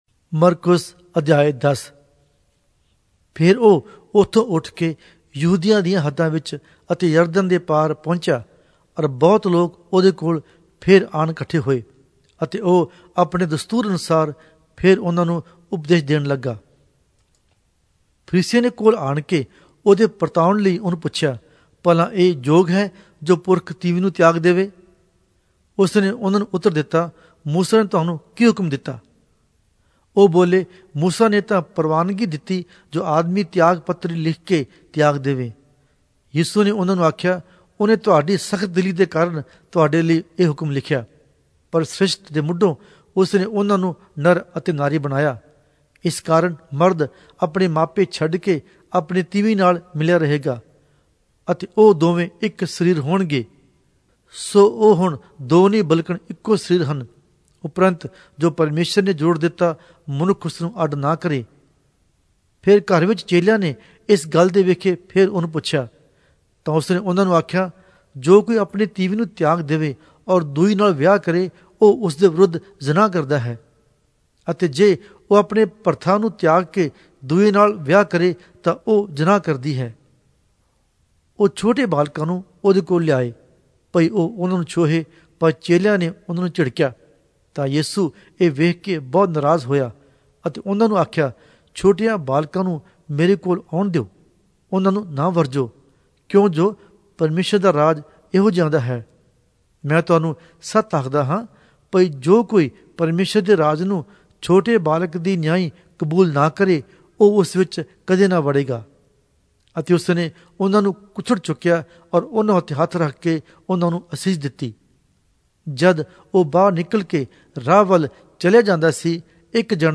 Punjabi Audio Bible - Mark 8 in Tev bible version